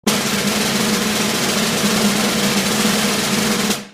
Другие рингтоны по запросу: | Теги: барабан